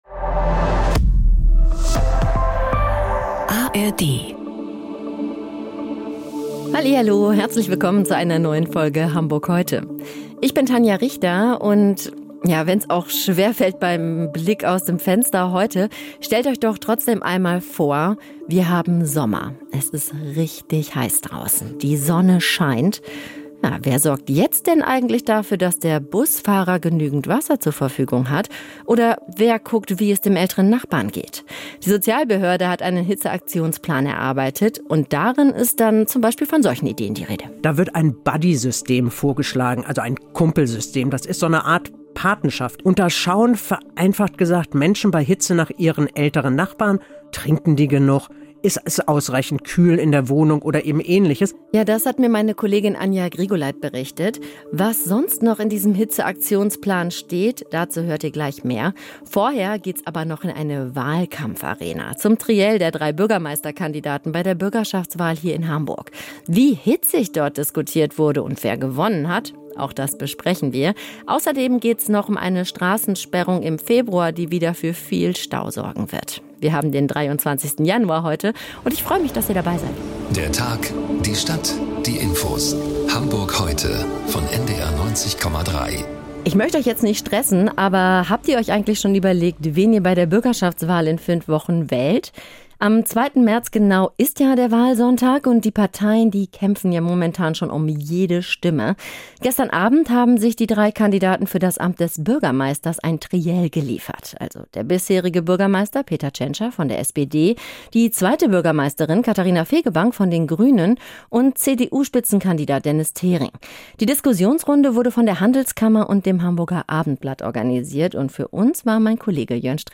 Unsere Reporterinnen und Reporter sind für Sie sowohl nördlich als auch südlich der Elbe unterwegs interviewen Menschen aus Wirtschaft, Gesellschaft, Politik, Sport und Kultur.